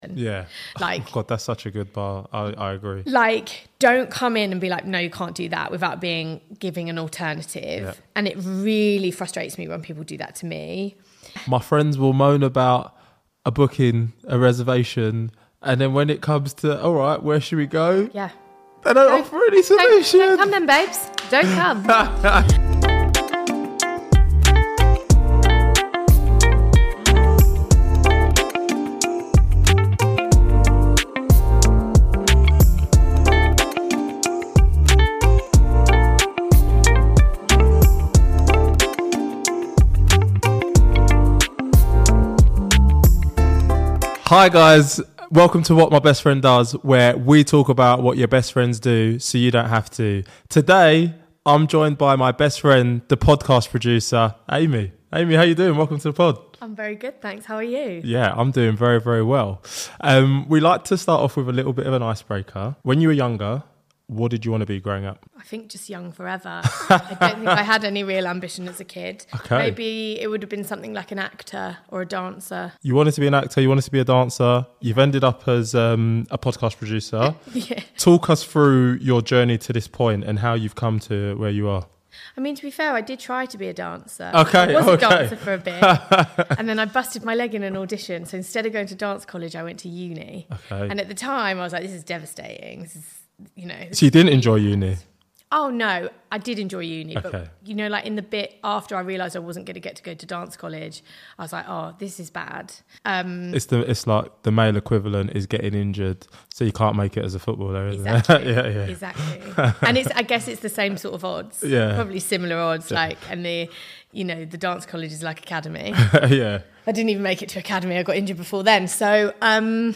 EP09 - Joining us today is an industry insider with a wealth of experience in Podcast.